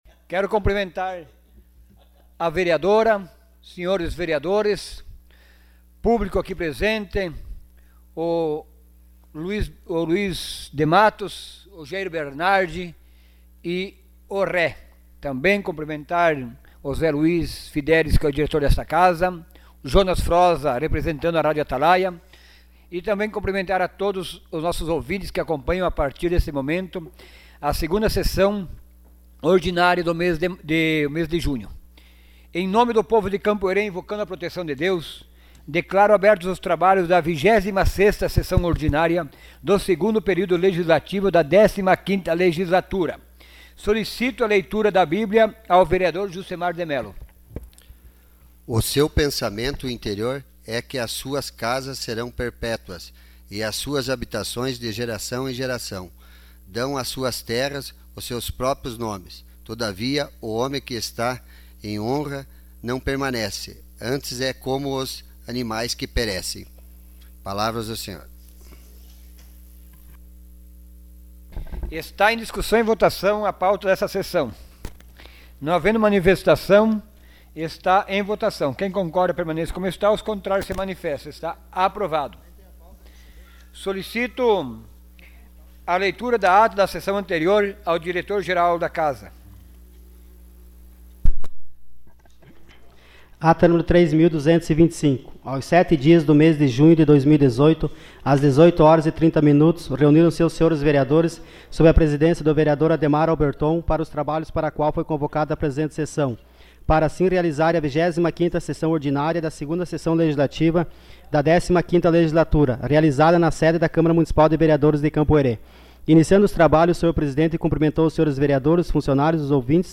Sessão Ordinária dia 11 de junho de 2018.